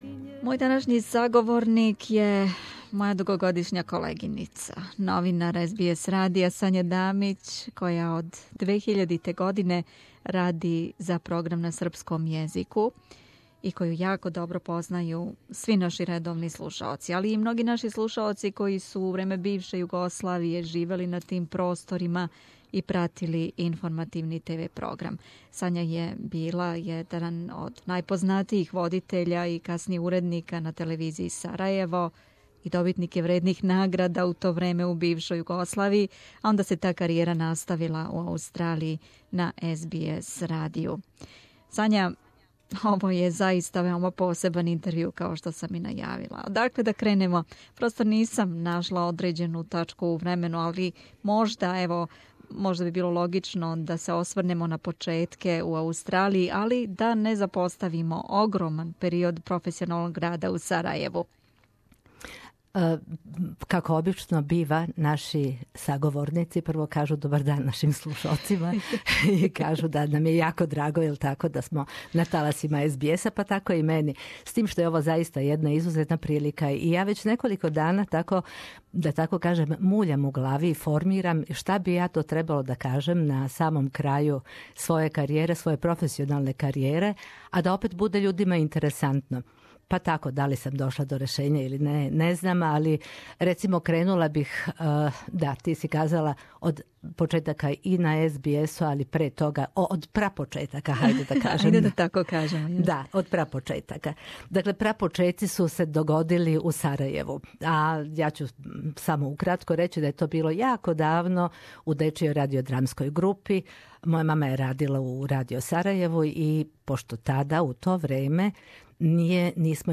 Присећамо се њенe изванредне каријере кроз врло посебан интервју.